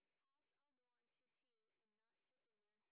sp14_train_snr20.wav